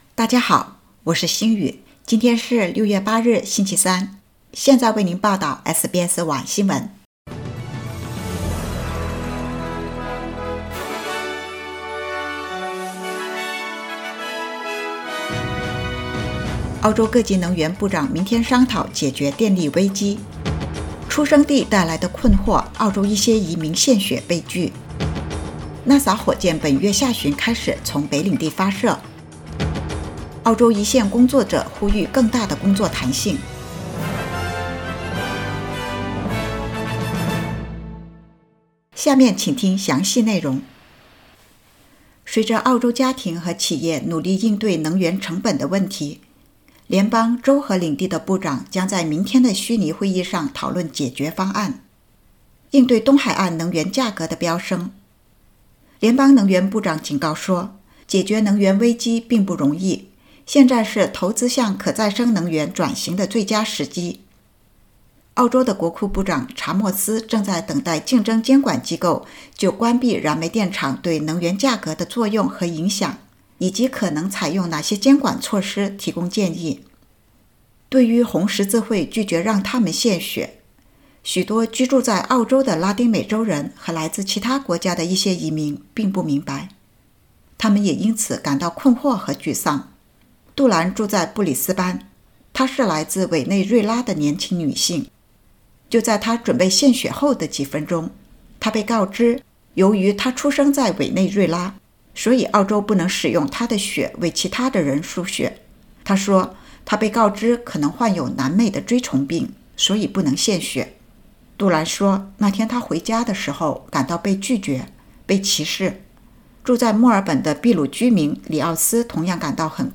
SBS晚新聞（2022年6月8日）
SBS Mandarin evening news Source: Getty Images